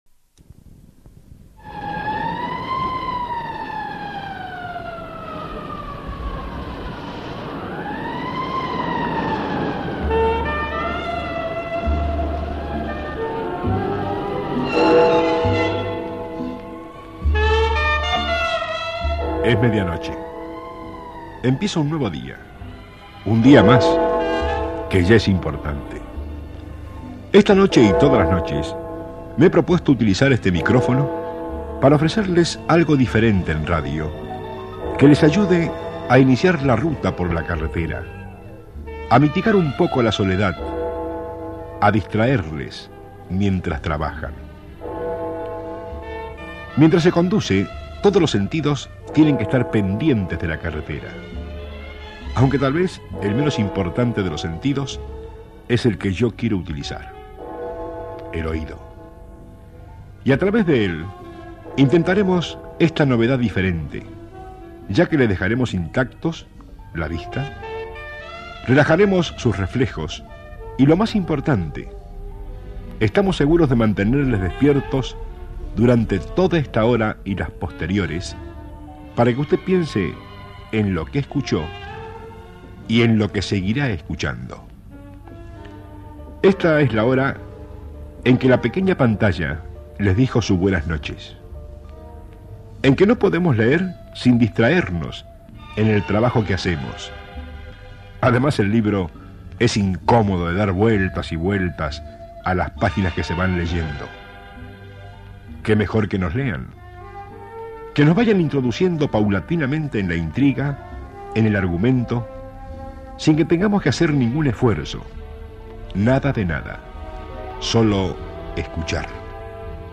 Sintonia del programa i presentació inicial de l'espai.
Musical